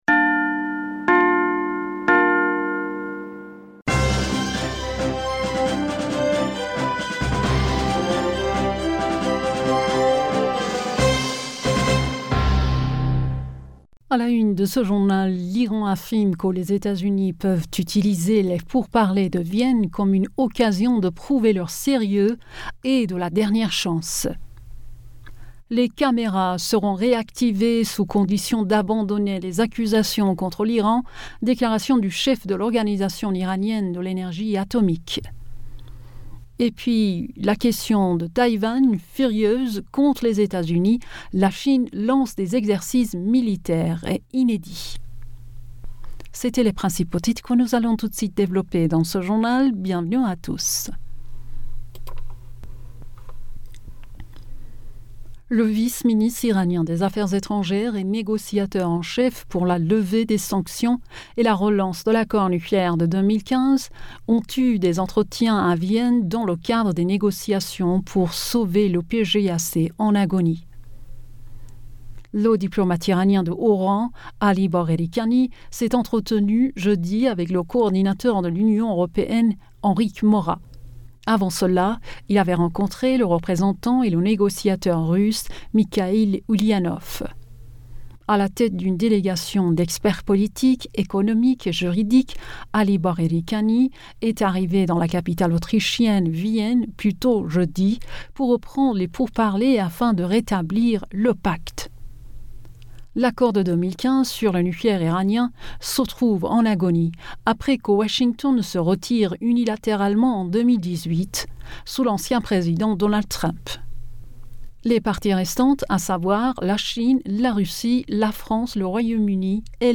Bulletin d'information Du 05 Aoùt